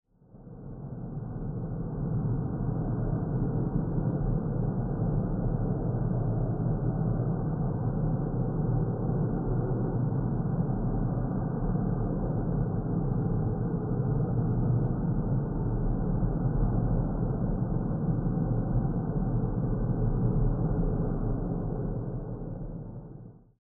Efecto de sonido bajo el agua
sumergido
Sonidos: Agua